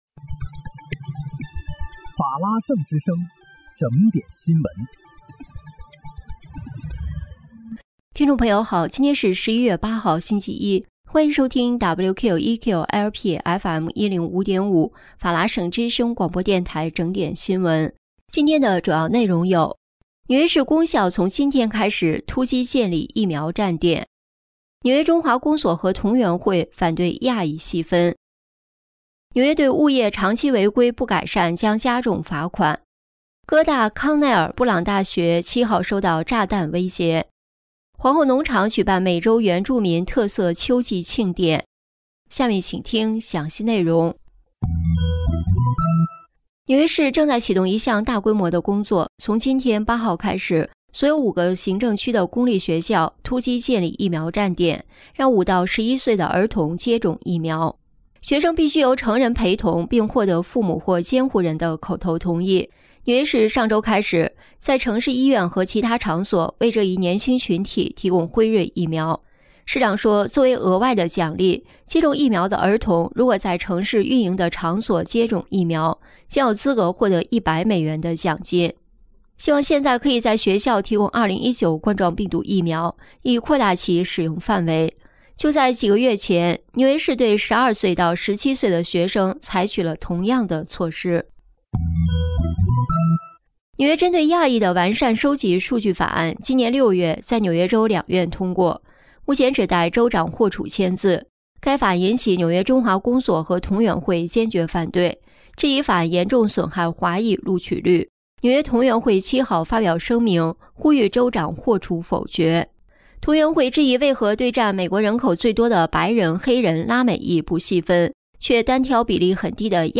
11月8日（星期一）纽约整点新闻